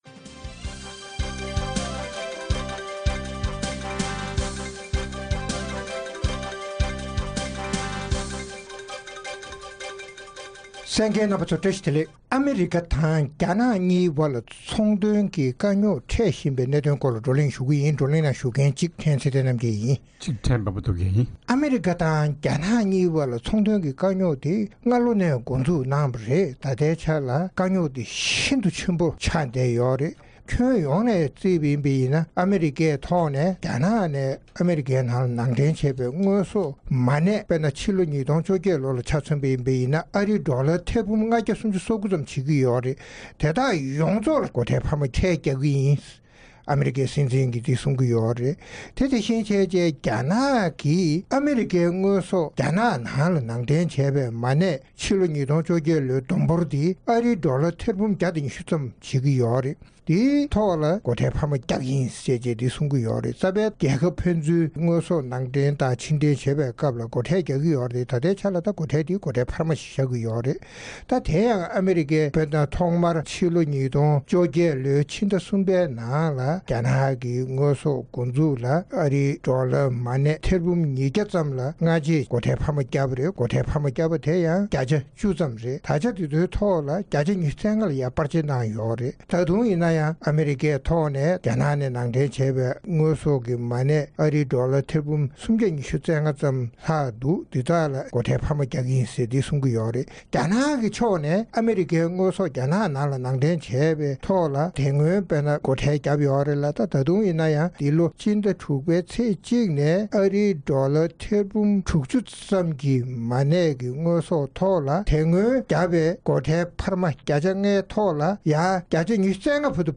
ཨ་རི་དང་རྒྱ་ནག་གཉིས་དབར་ཚོང་གི་དཀའ་རྙོག་སེལ་ཐབས་སུ་གྲོལ་མོལ་རྒྱུན་རིང་གནང་ཡང་གྲོས་མཐུན་བྱུང་མེད་པ་དང་། ད་ཆ་ཕན་ཚུན་ནས་དངོས་ཟོག་ནང་འདྲེན་བྱས་པར་སྒོ་ཁྲལ་སྤར་ཆ་གནང་སྟེ་དཀའ་རྙོག་ཆེ་རུ་ཕྱིན་པའི་སྐོར་རྩོམ་སྒྲིག་འགན་འཛིན་རྣམ་པས་བགྲོ་གླེང་གནང་བ་གསན་རོགས་གནང་།